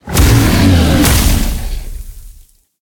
Sfx_creature_snowstalker_death_land_01.ogg